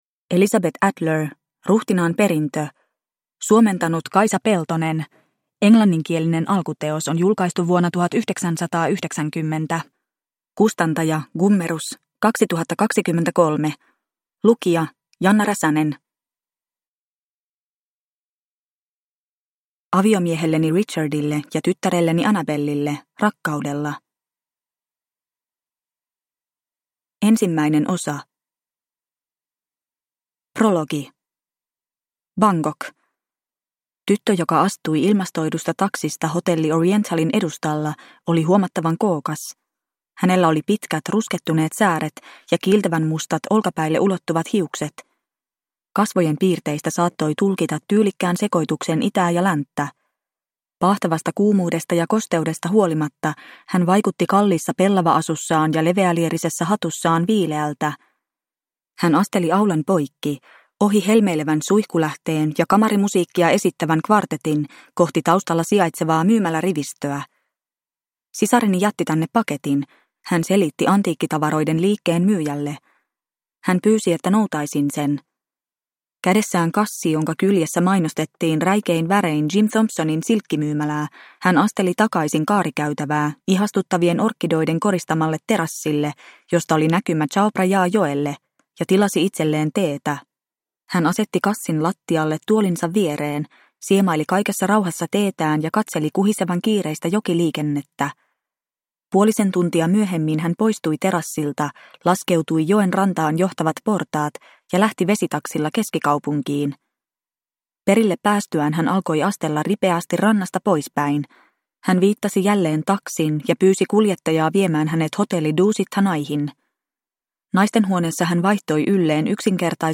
Ruhtinaan perintö – Ljudbok – Laddas ner